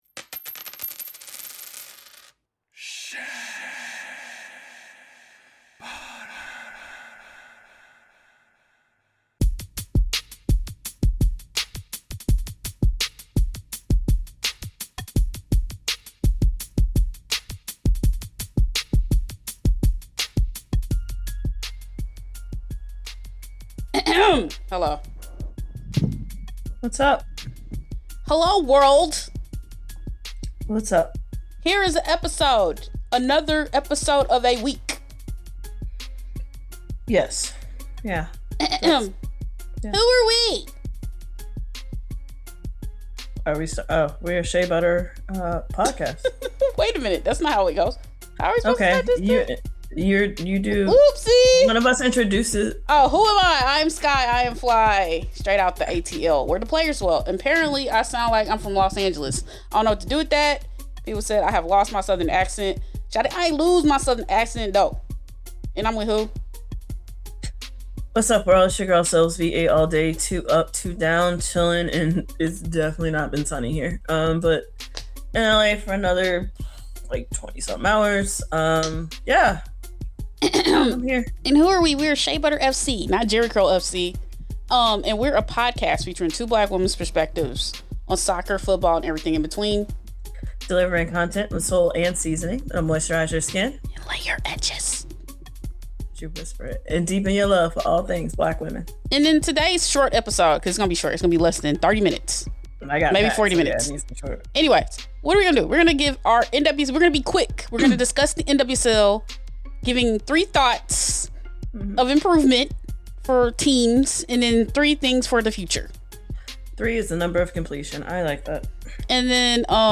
His beat appears at the intro piece at the beginning of the episode.
Her beats appear during SBFC Superlatives and at the end of the episode.